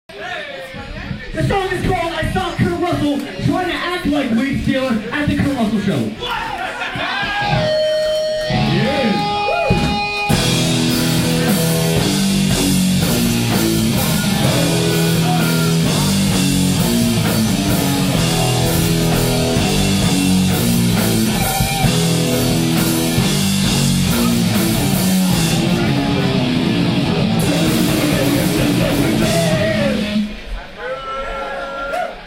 Live @ the last house on the left